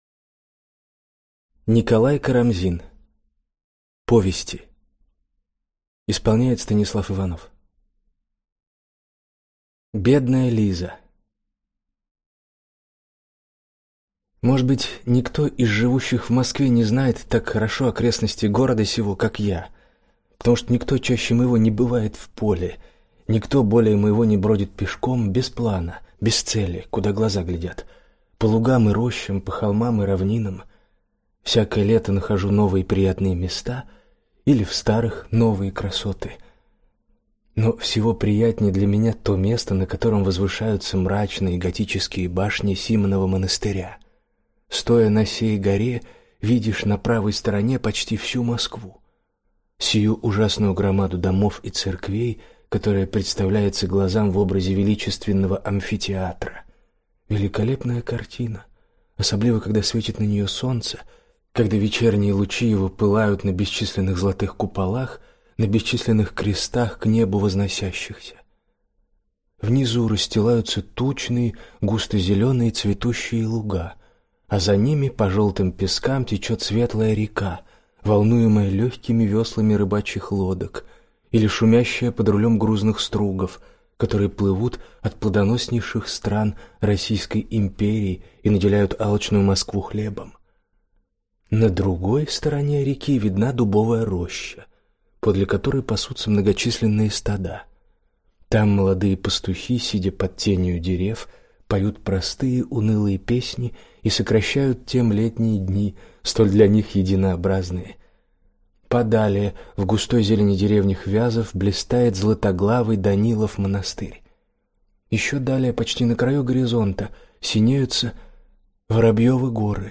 Аудиокнига Бедная Лиза (сборник) | Библиотека аудиокниг